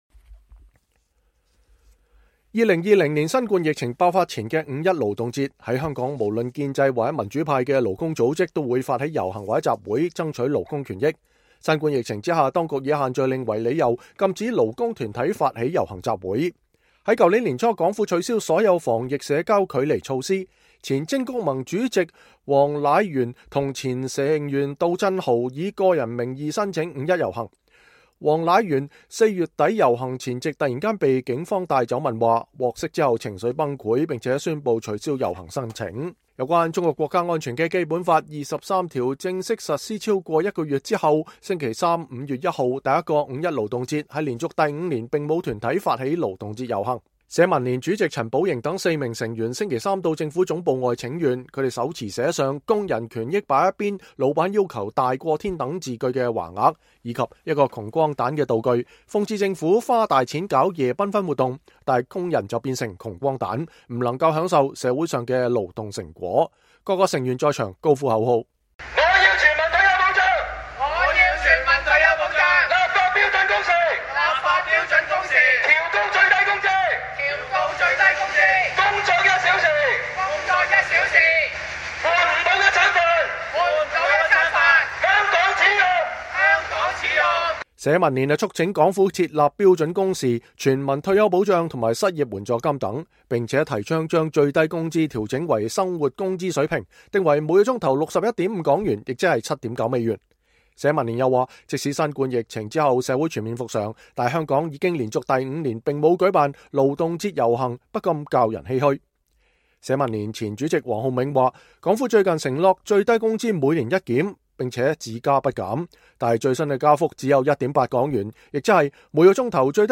社民連成員並在場高呼“我要全民退休保障、立法標準工時、調高最低工資、工作一小時、換唔(不)到一餐(頓)飯、香港恥辱“等抗議口號。